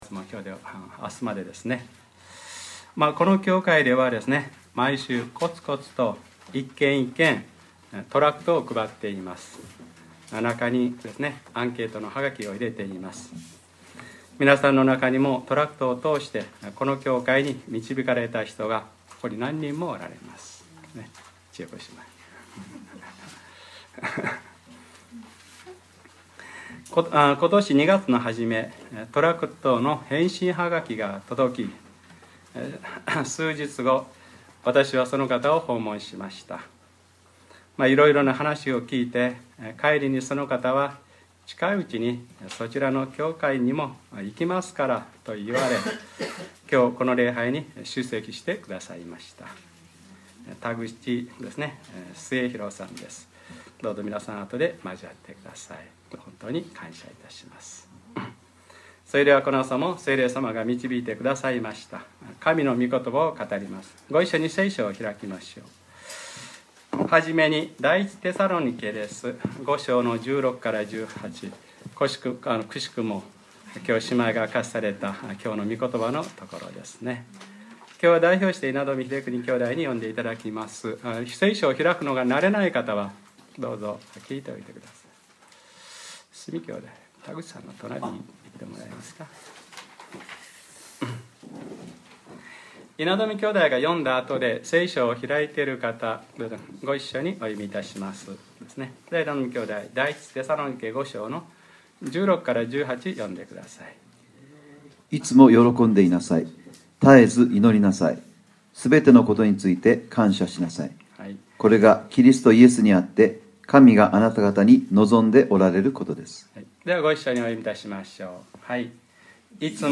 2014年 3月30日（日）礼拝説教『これが神があなたがたに望んでおられることです』